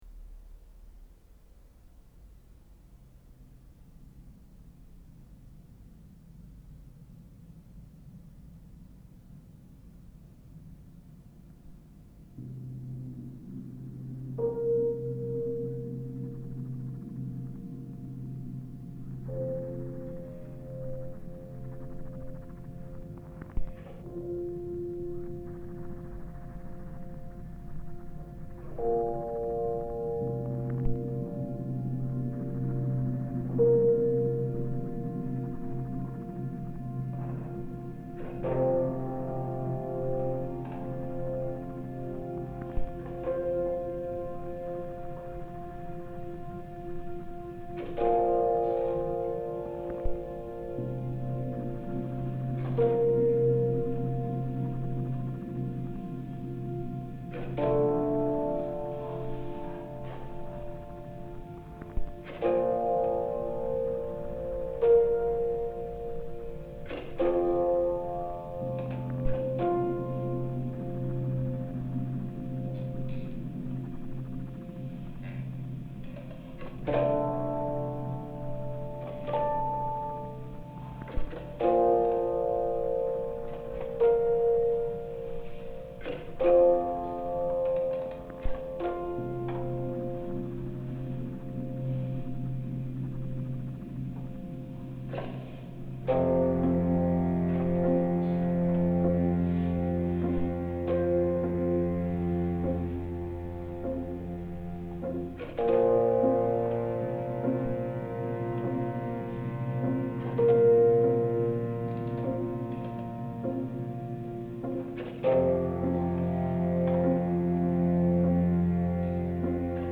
Ambient piano for the end of something good